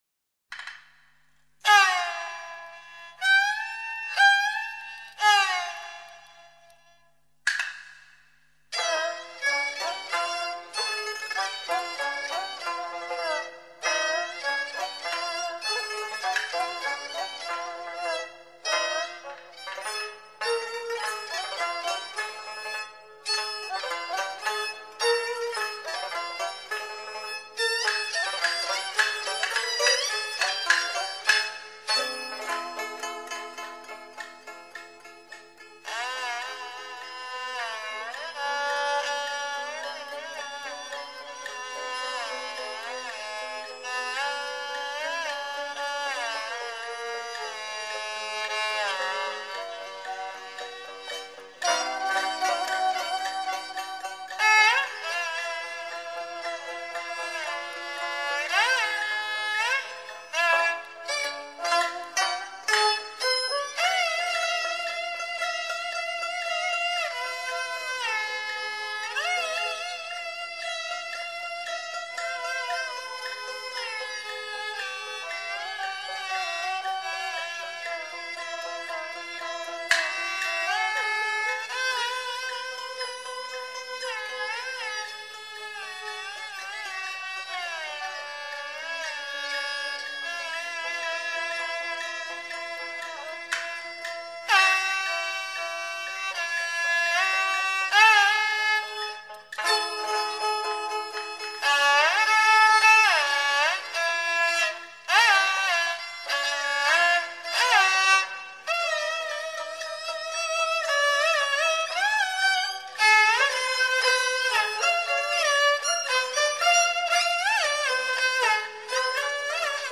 下面这首《沙家浜·智斗》片段，是什么乐器摸仿的唱腔？